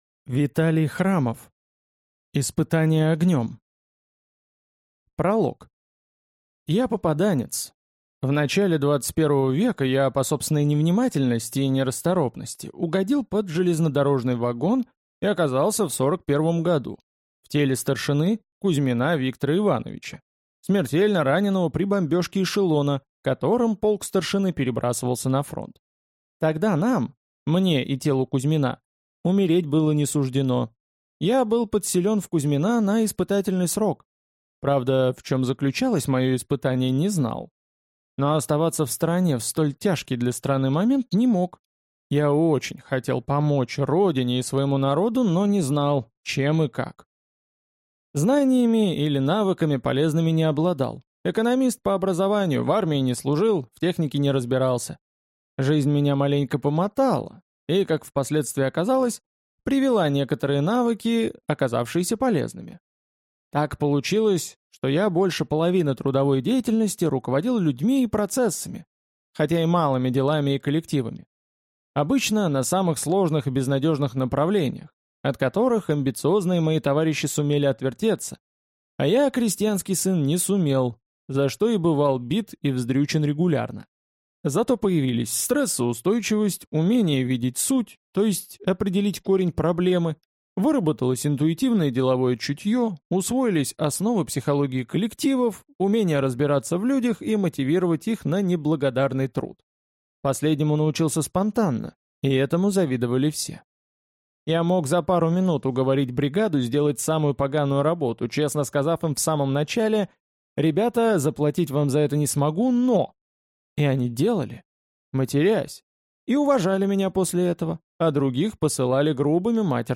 Аудиокнига Испытание огнем | Библиотека аудиокниг